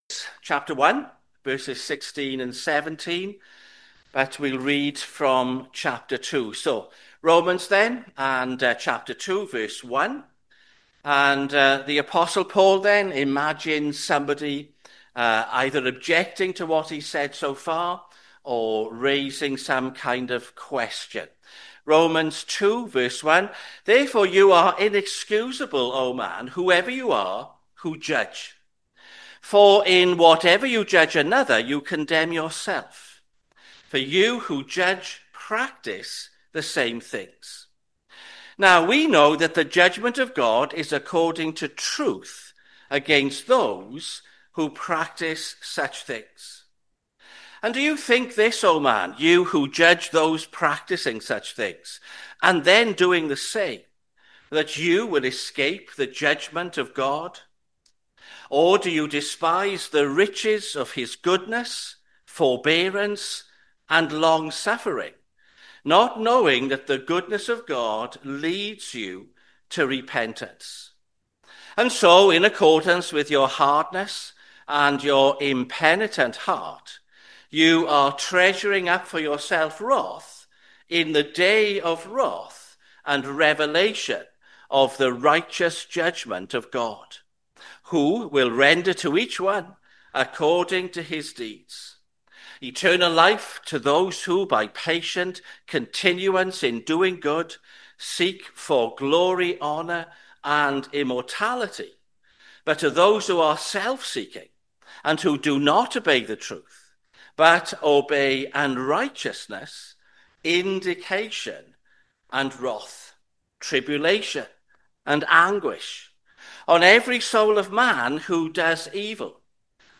sermon-2025-f-1st-June-pm.mp3